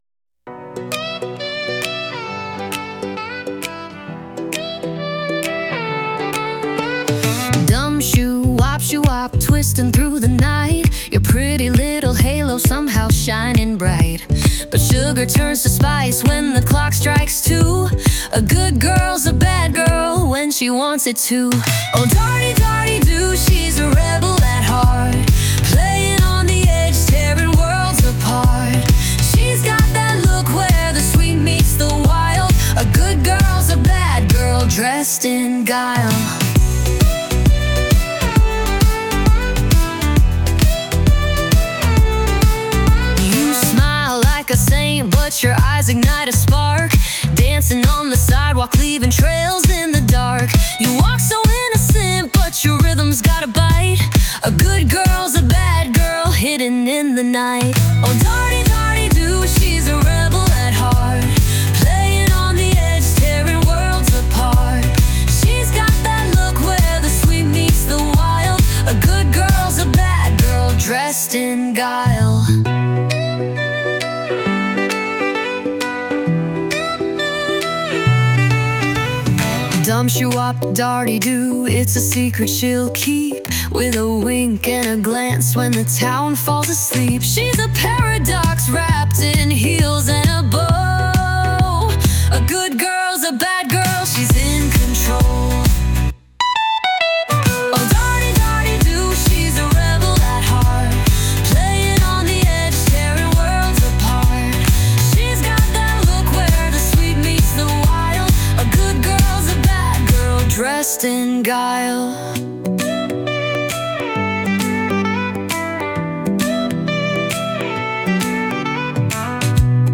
Sprechgesang